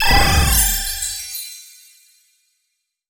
vanish_spell_flash_potion_02.wav